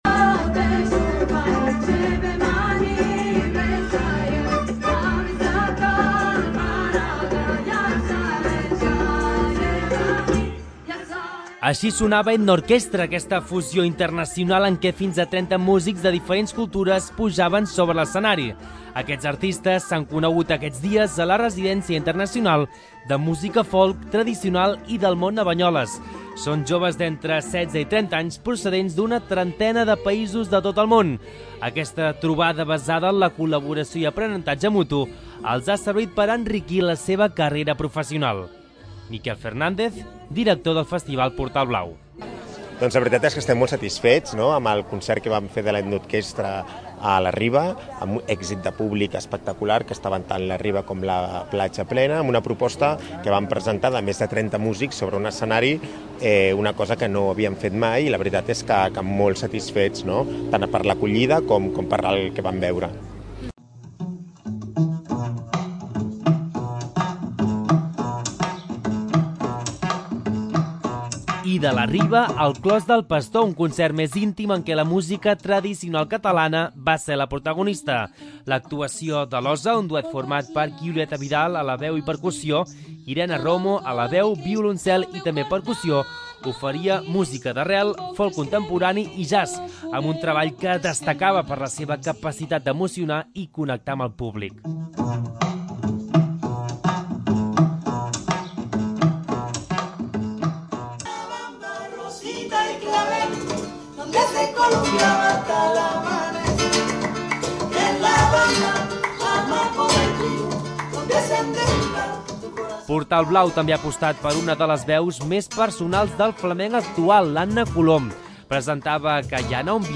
Així sonava l'Ethno Orchestra, aquesta fusió internacional en què fins a trenta músics de diferents cultures pujaven sobre l'escenari.
Un concert més íntim en què la música tradicional catalana va ser la protagonista.